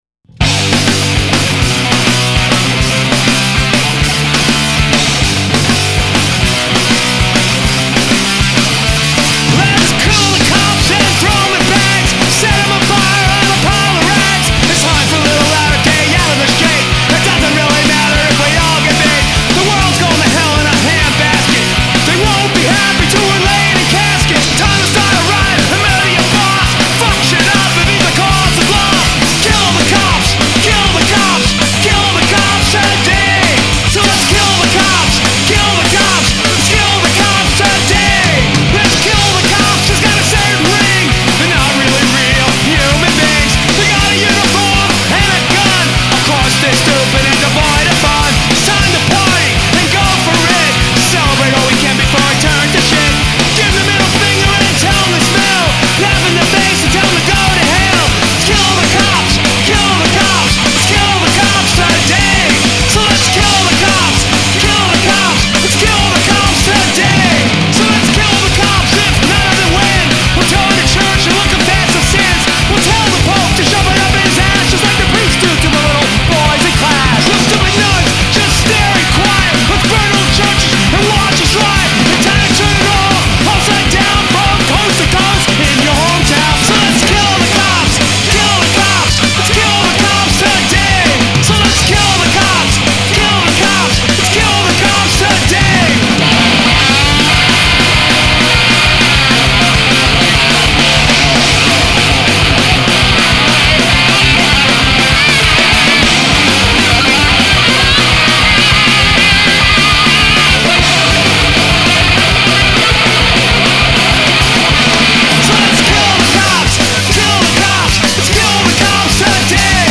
(Punk / Hardcore U.S., since 1980 !)
enregistré aux Cyclone Studios, New York